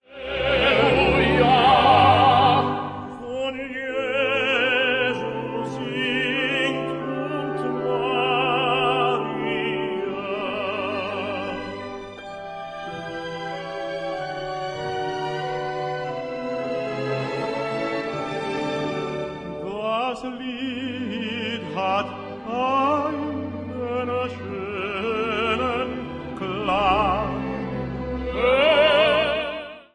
music: Traditional
key: G-major